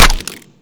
weap_sml_gndrop_3.wav